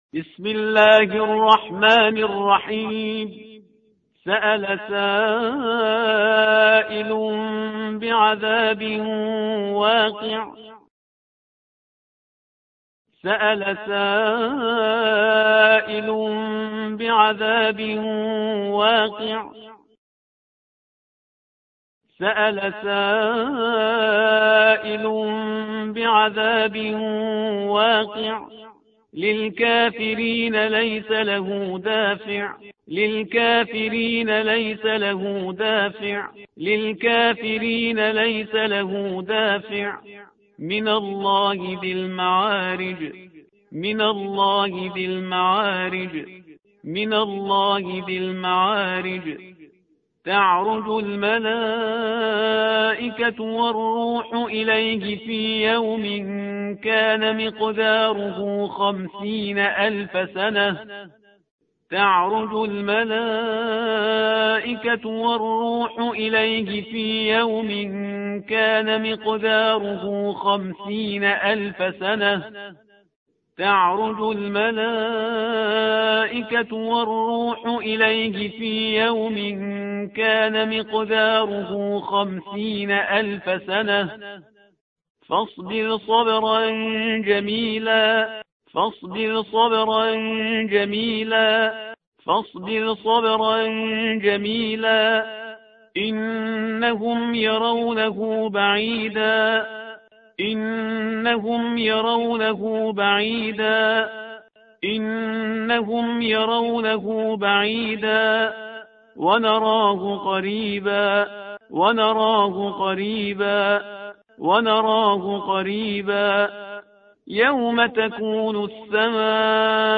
فایل های صوتی سه بار تکرار از استاد پرهیزگار- سوره معارج